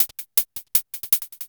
Hats 01.wav